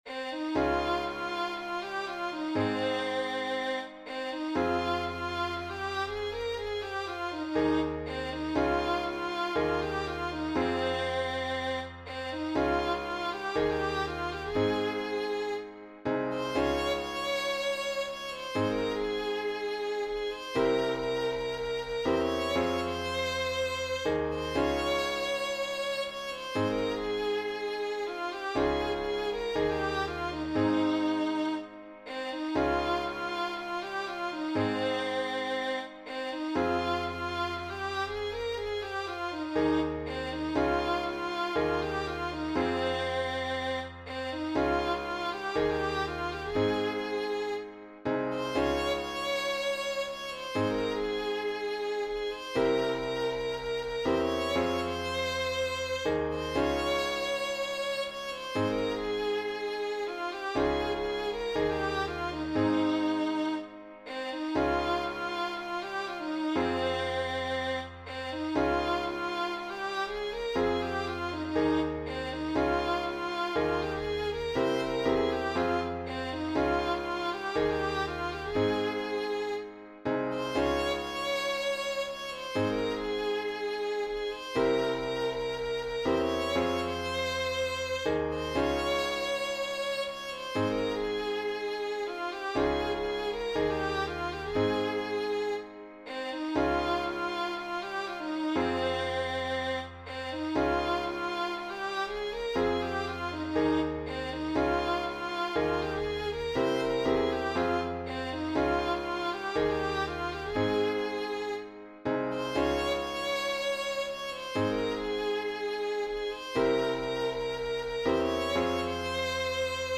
Hymn composed by